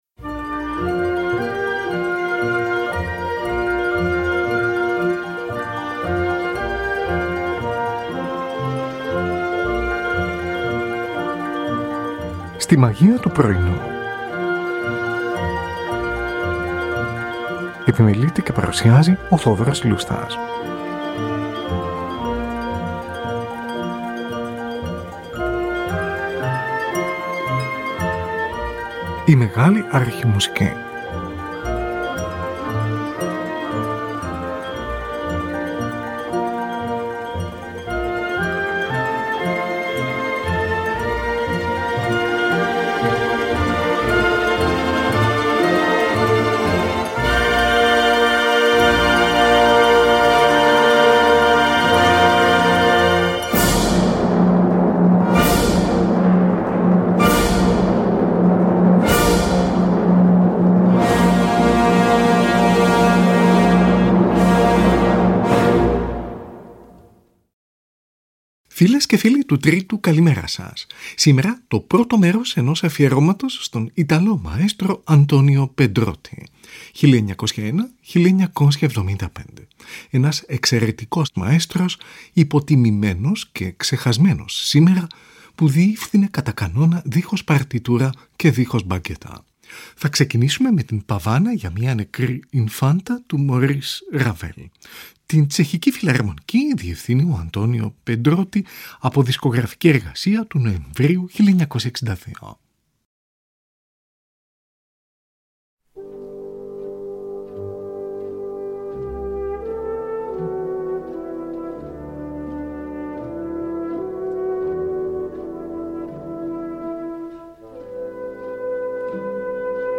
συμφωνικό ποίημα